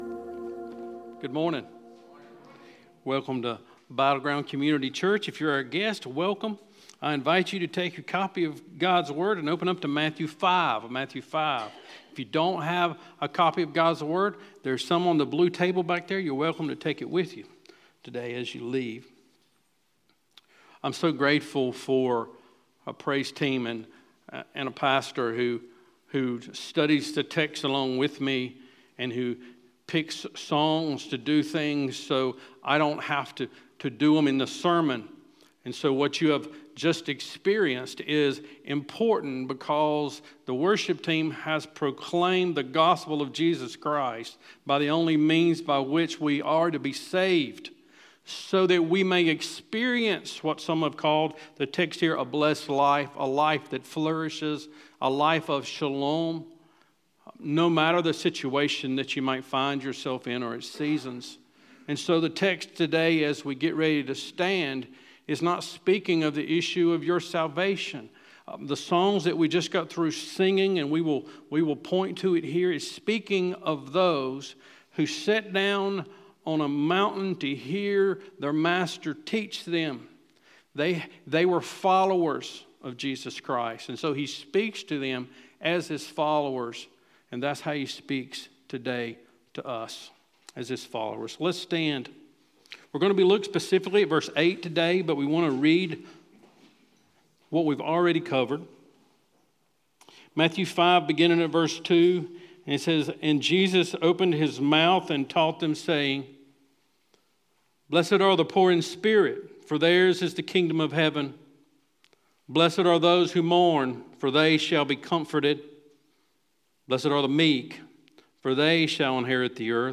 Battleground Community Church Sermons